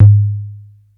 SouthSide Kick Edited (8).wav